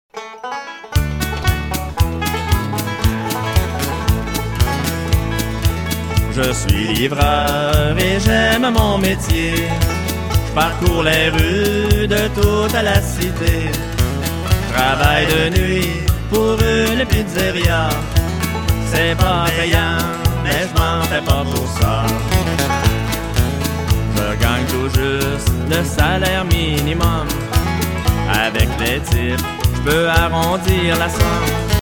Enregistrement au studio